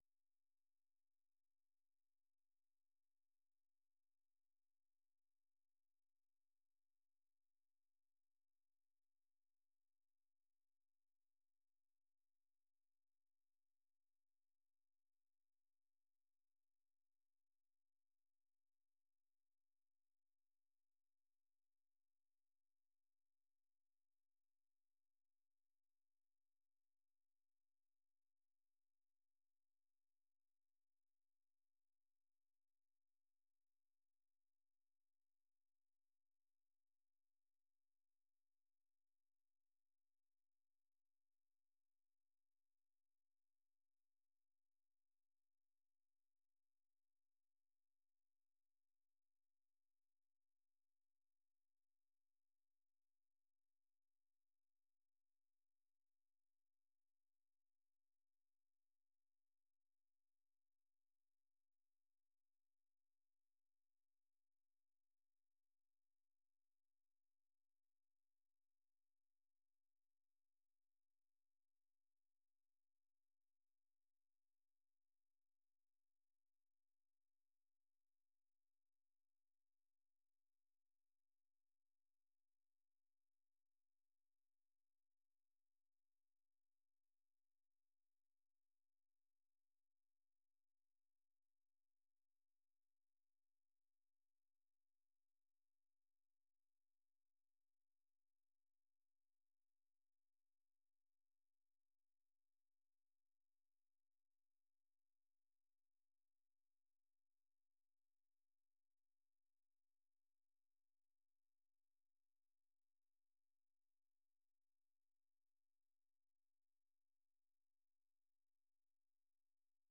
ፈነወ ትግርኛ ብናይ`ዚ መዓልቲ ዓበይቲ ዜና ይጅምር ። ካብ ኤርትራን ኢትዮጵያን ዝረኽቦም ቃለ-መጠይቓትን ሰሙናዊ መደባትን ድማ የስዕብ ። ሰሙናዊ መደባት ሰሉስ፡ ኤርትራውያን ኣብ ኣመሪካ/ ኣመሪካና